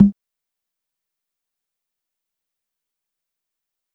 Clink (Get It Together).wav